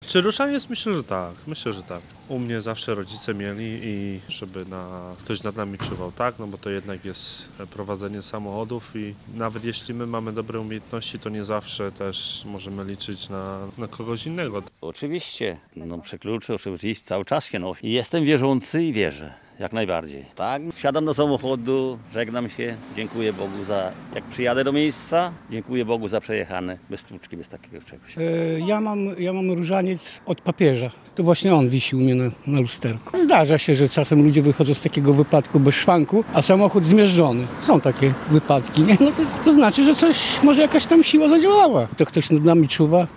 Zapytaliśmy kierowców z naszego regionu, czy posiadają w swoich autach święty symbol oraz czy wierzą w ochronę podczas podróży przez patrona:
Aktualności | Łomża | Pilne 3 | Sonda | Wiara